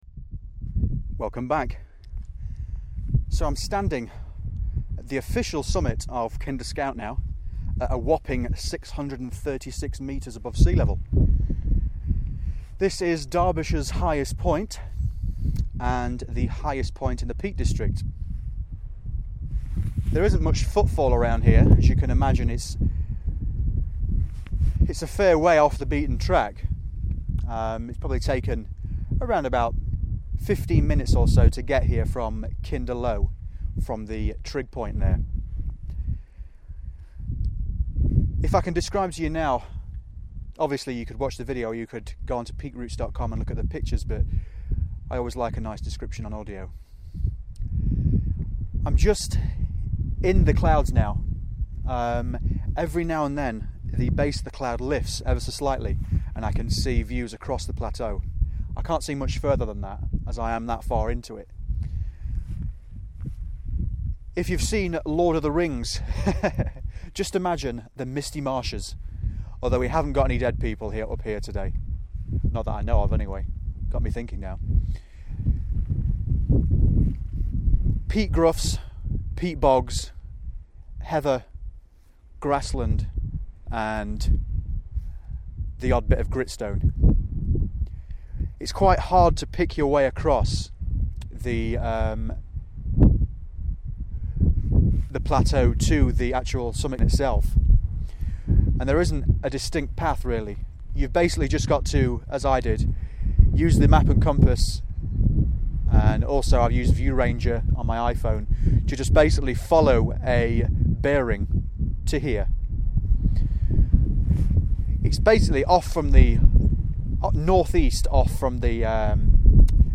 This is part 2 of a walk starting from Barber Booth. I then walk up Jacobs Ladder, over to Kinder Low, to the official summit area, have lunch at Pym Chair, pass Crowden, Grindsbrook and then head down via Ringing Roger to Edale before crossing the fields back to Barber Booth